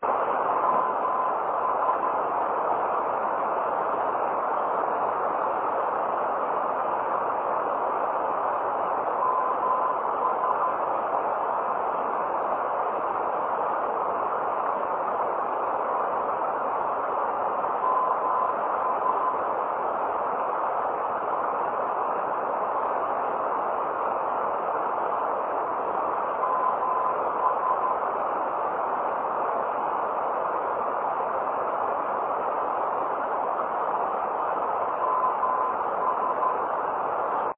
NDB Sounds